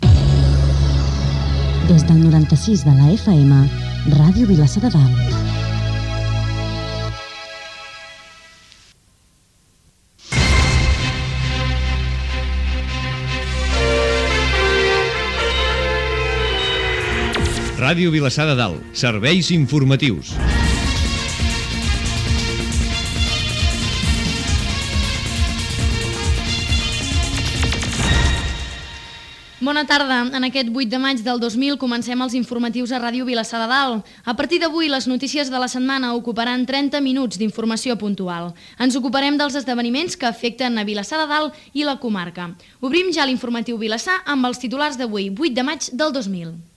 Indicatiu de l'emissora, careta del programa, presentació inicial
Informatiu